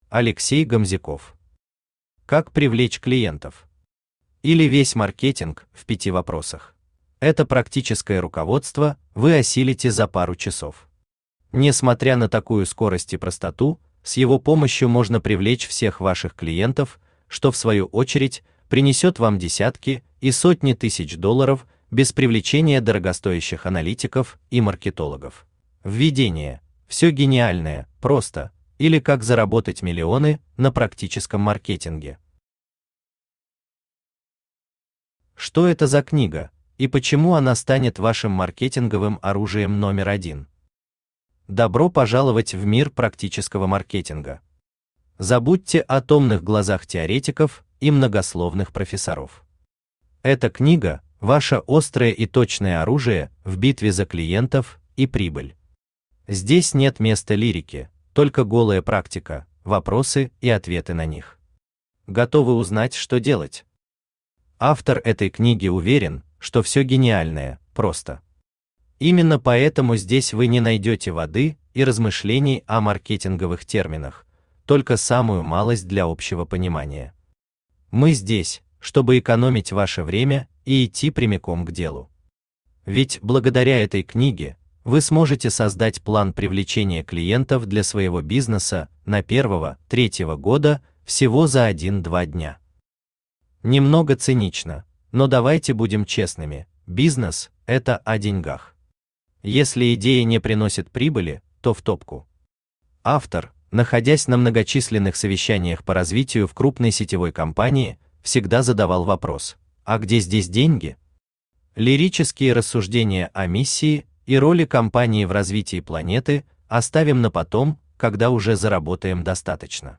Автор Алексей Геннадьевич Гомзяков Читает аудиокнигу Авточтец ЛитРес.